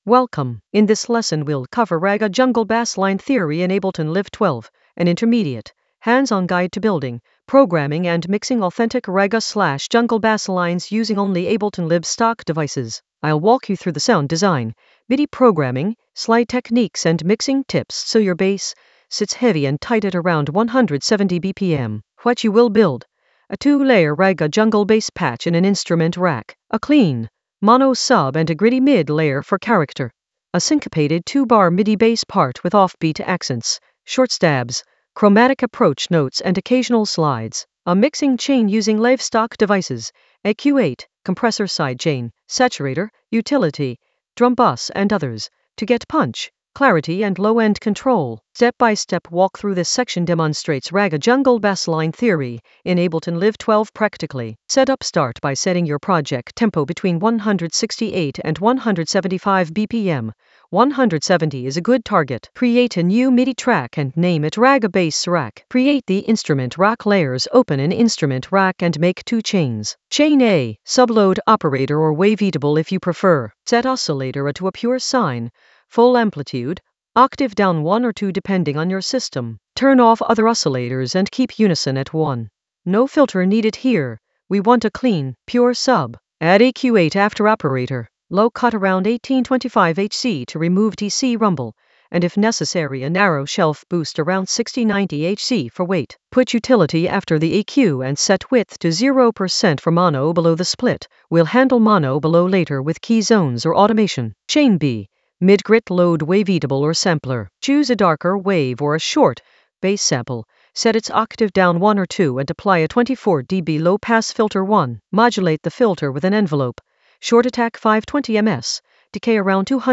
An AI-generated intermediate Ableton lesson focused on Ragga Jungle Bassline Theory in Ableton Live 12 in the Basslines area of drum and bass production.
Narrated lesson audio
The voice track includes the tutorial plus extra teacher commentary.